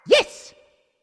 One of Mario's voice clips in Mario Party 6